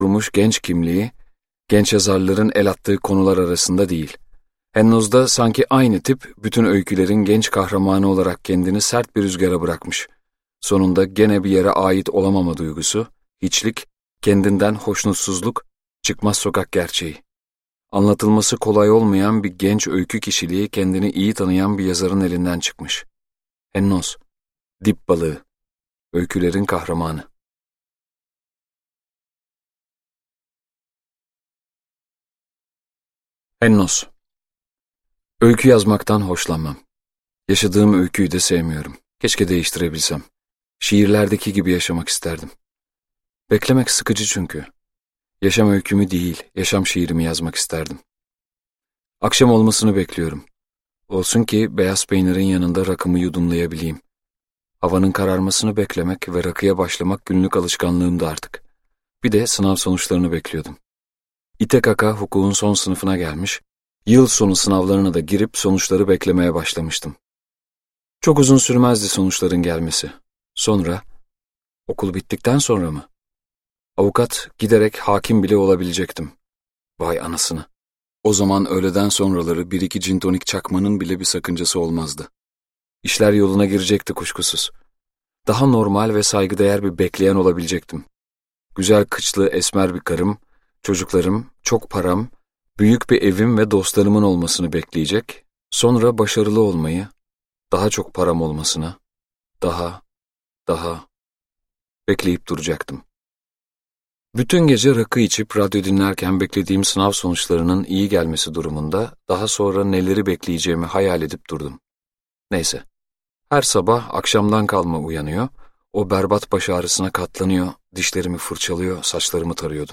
Hennoz - Seslenen Kitap